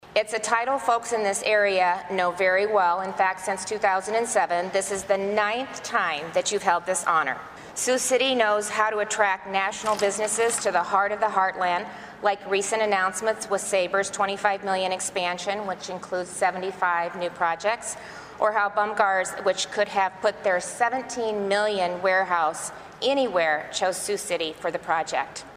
THE EVENT TOOK PLACE AT THE NEW $15 MILLION SIOUXLAND EXPO CENTER NEAR DOWNTOWN SIOUX CITY.